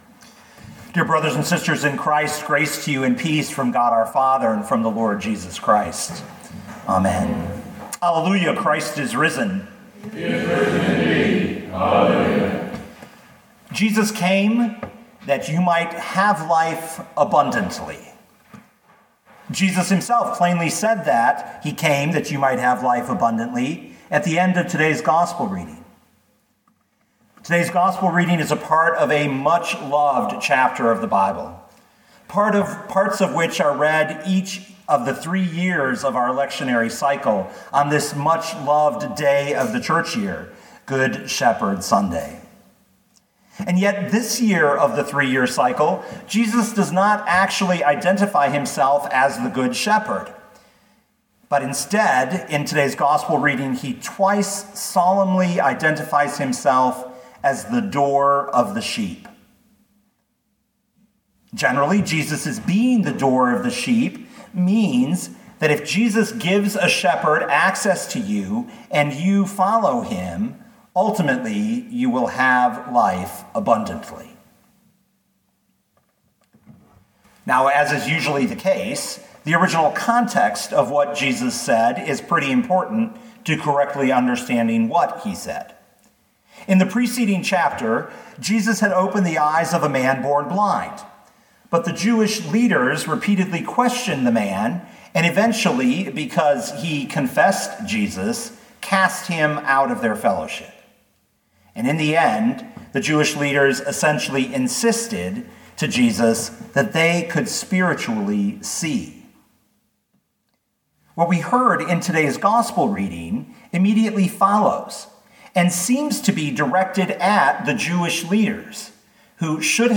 2020 John 10:1-10 Listen to the sermon with the player below, or, download the audio.